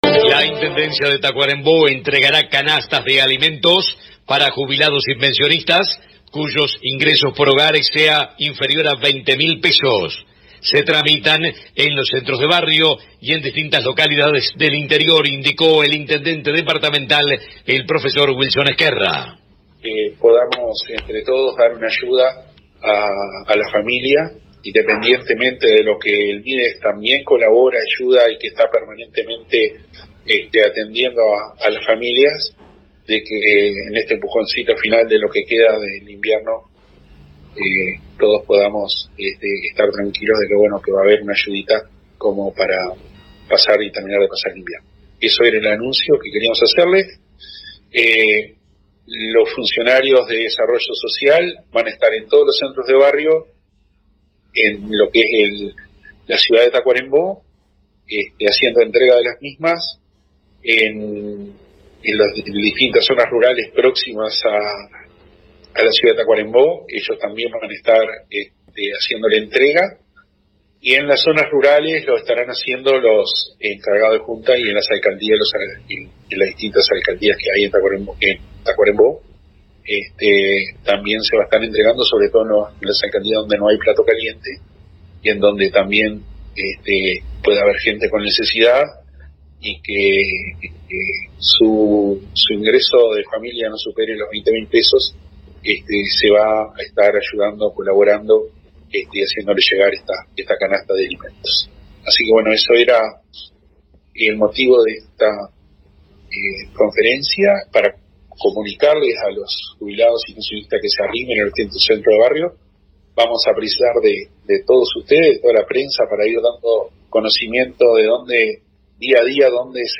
En conferencia de prensa, el gobernante dijo que independientemente de las ayudas que aporta el Mides, se estará dando un «empujoncito final» por lo que queda de invierno, a este grupo de personas más necesitadas en los Centros de Barrio de la ciudad de Tacuarembó y en diferentes localidades del interior del departamento.
Escucha a Wilson Ezquerra aquí: